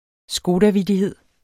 Udtale [ ˈsgoːda- ]